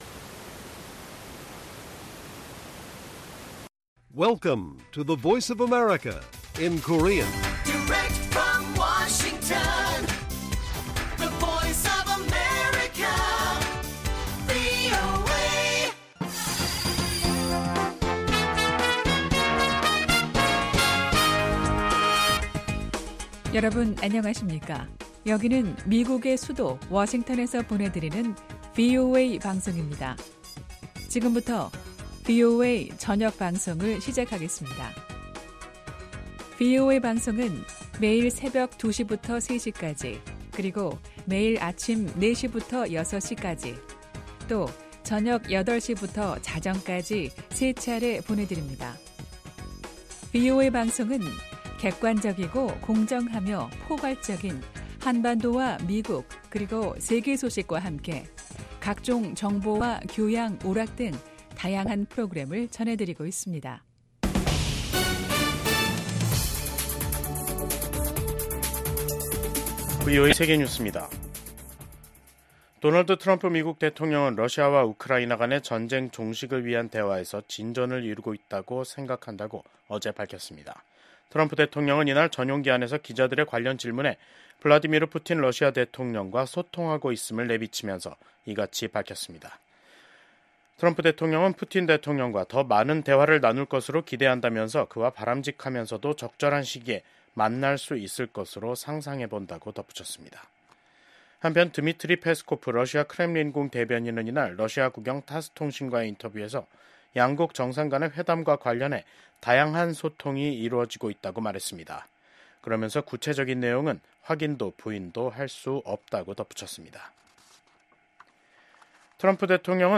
VOA 한국어 간판 뉴스 프로그램 '뉴스 투데이', 2025년 2월 10일 1부 방송입니다. 도널드 트럼프 미국 대통령이 한반도 안정을 위한 노력을 계속하고 김정은 위원장과도 관계를 맺을 것이라고 밝혔습니다. 미국 정부 고위 당국자가 북한의 완전한 비핵화가 트럼프 행정부의 변함없는 목표라는 점을 재확인했습니다. 김정은 북한 국무위원장은 미국이 세계 각지 분쟁의 배후라고 주장하면서 핵 무력 강화 방침을 재확인했습니다.